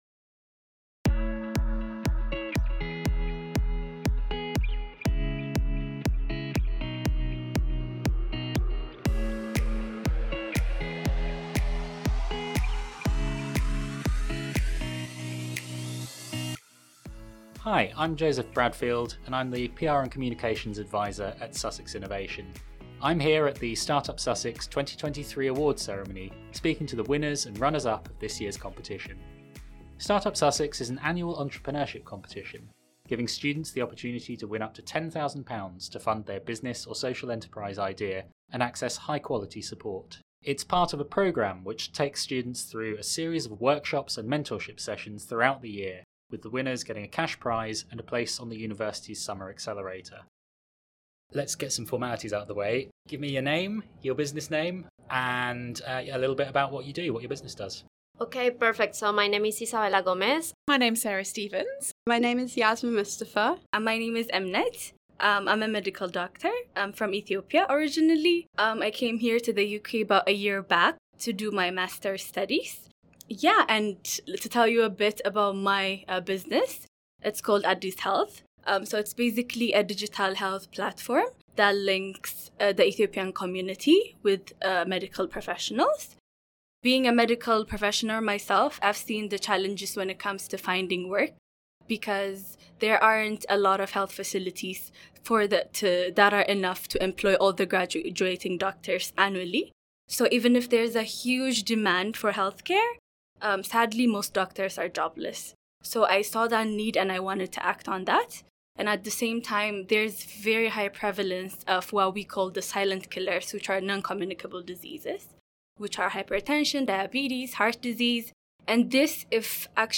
speaking to the winners here: